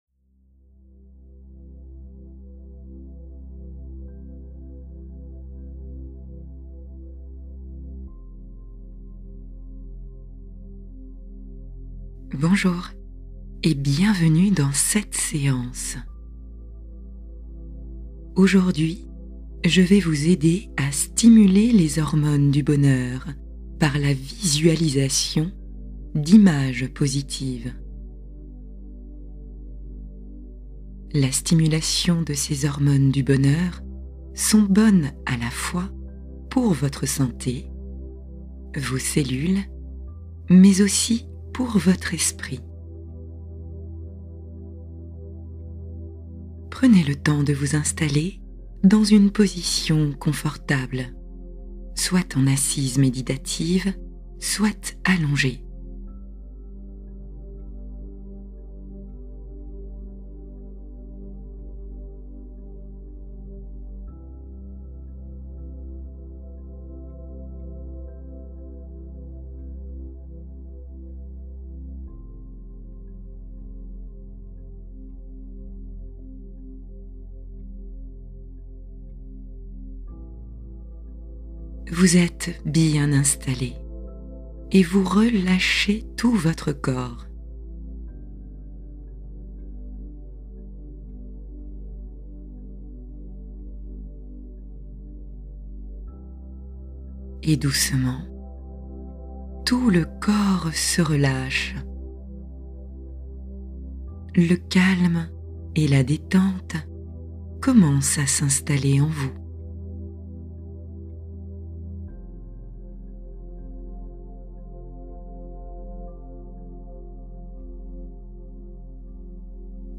Être un lion confiant : méditation ludique pour courage et assurance des enfants